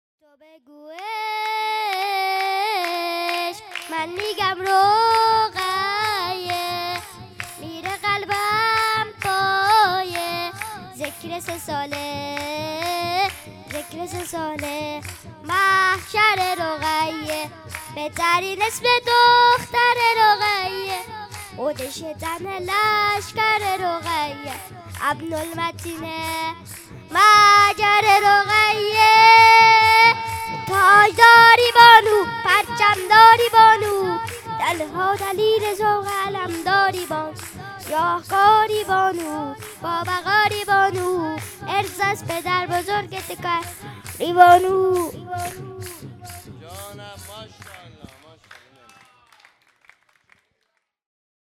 ویژه مراسم جشن بزرگ ولادت امام زمان(عج) و حضرت علی اکبر(ع) و جشن پیروزی انقلاب